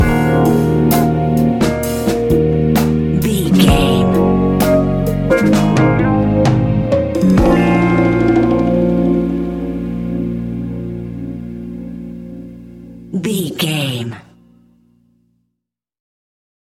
Ionian/Major
A♯
hip hop
instrumentals